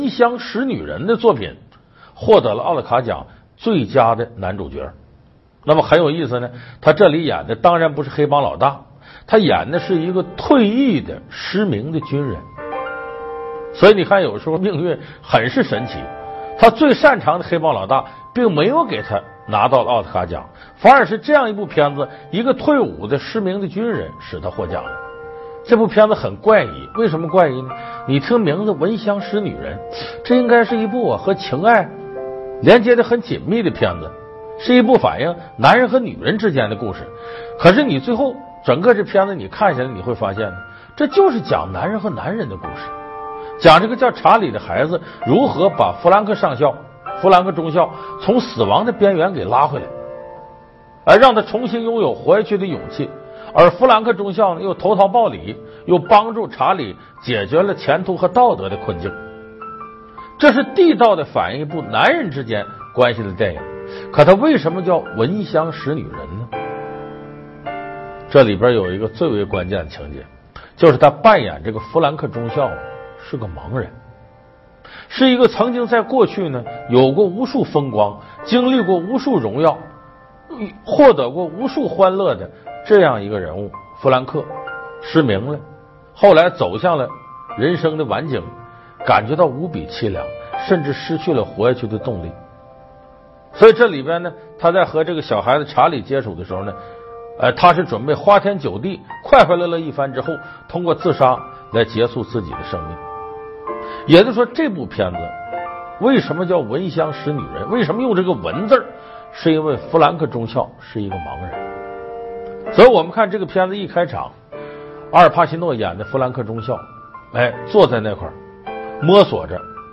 求这首钢琴曲，知道名就行，中毒太深了，都想自己弹了